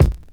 • 2000s Mid-Range Bass Drum One Shot G# Key 336.wav
Royality free kick one shot tuned to the G# note. Loudest frequency: 302Hz